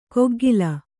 ♪ koggila